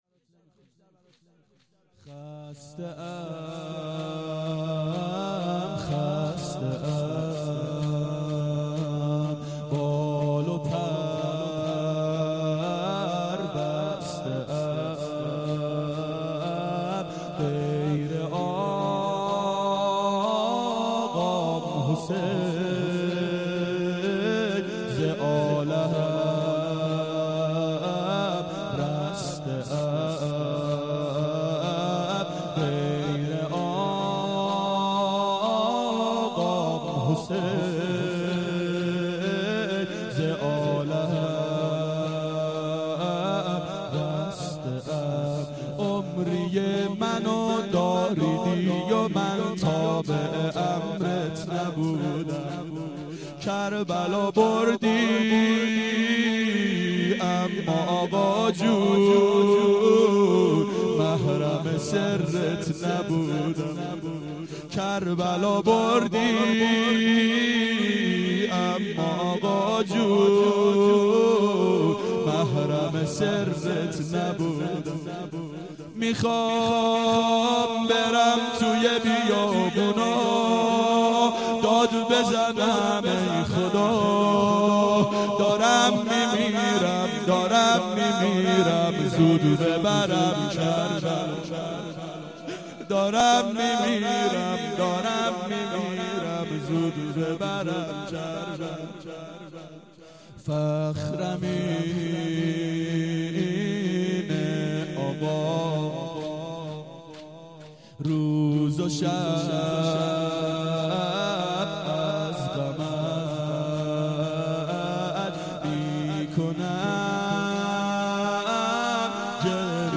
دهه اول صفر سال 1391 هیئت شیفتگان حضرت رقیه سلام الله علیها (شام غریبان)